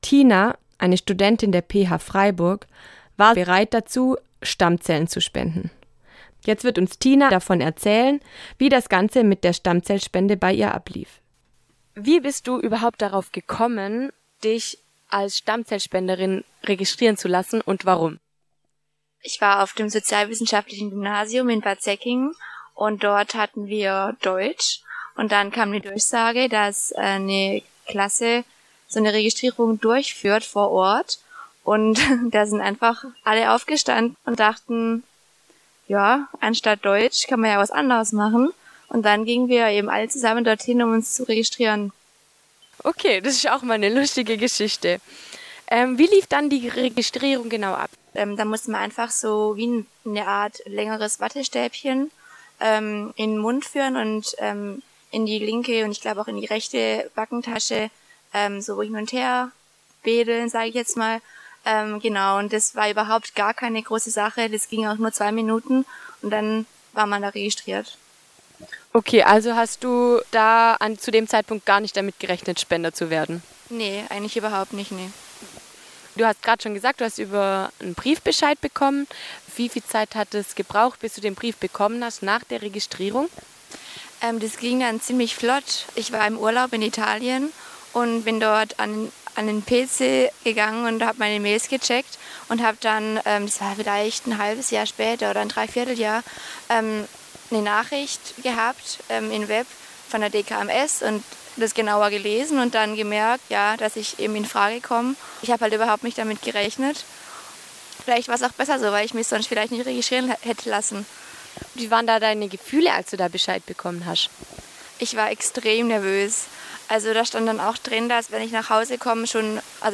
— Wir bitten, die leider mangelhafte Tonqualität zu entschuldigen —